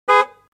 جلوه های صوتی
دانلود صدای ماشین 25 از ساعد نیوز با لینک مستقیم و کیفیت بالا